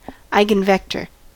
eigenvector: Wikimedia Commons US English Pronunciations
En-us-eigenvector.WAV